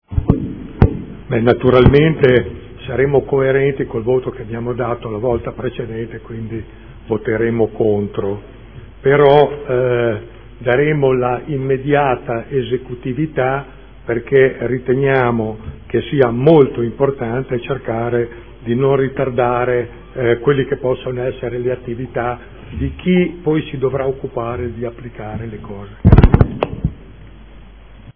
Gian Carlo Pellacani — Sito Audio Consiglio Comunale
Seduta del 28/04/2014 Rendiconto della gestione del Comune di Modena per l’esercizio 2013 - Approvazione - dichiarazione di voto.